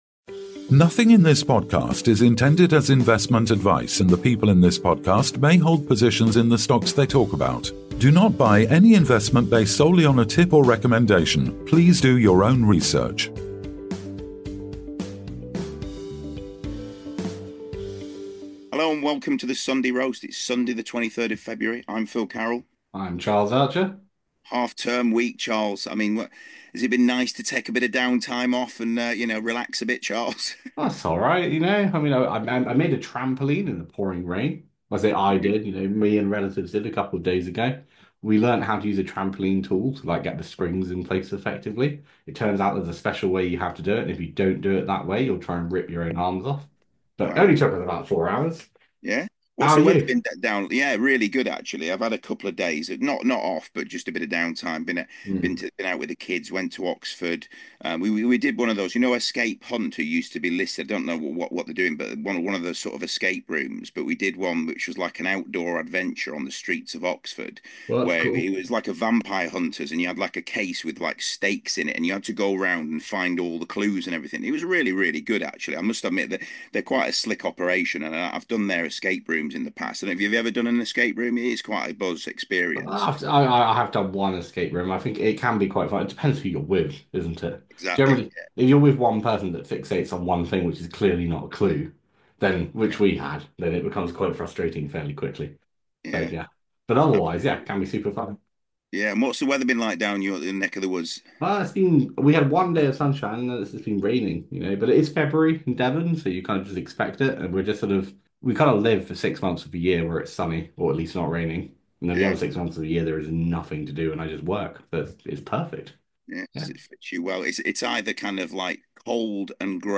All this, alongside the usual banter, movie talk, and more!